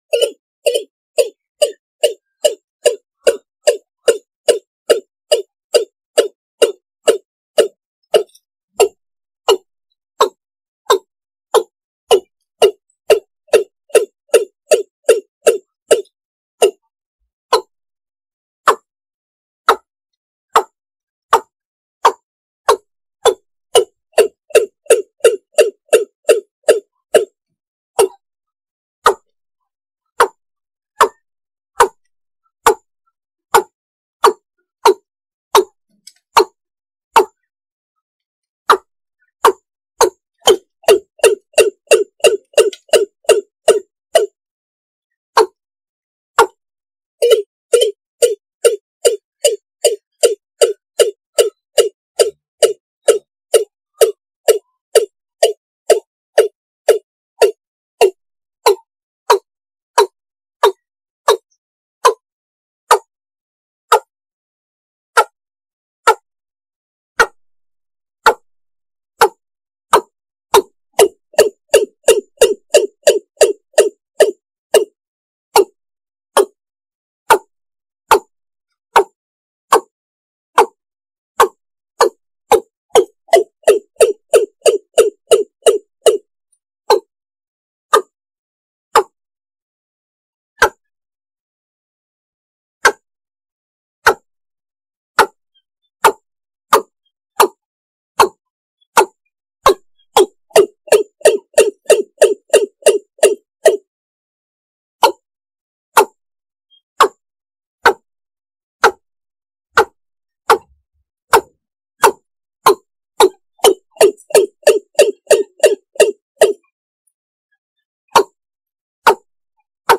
Tiếng Cúm Núm
Chủ đề: đồng ruộng/ đầm lầy tiếng Cúm Núm tiếng gà đồng tiếng Trích Ré
Thể loại: Tiếng chim
tieng-cum-num-www_tiengdong_com.mp3